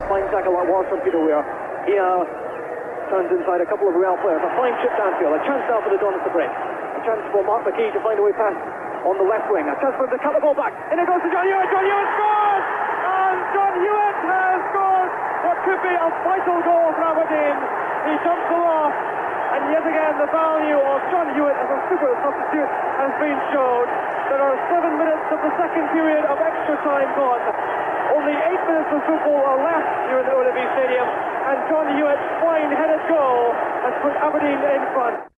Listen to the Northsound commentary of Hewitt's winning goal in Gothenburg '83 !